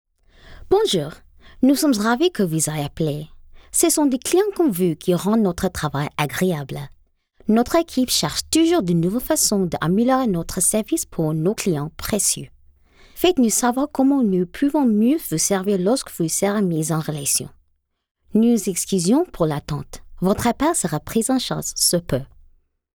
Professional, broadcast-ready voice-overs delivered in both English and French.
French (Commercial)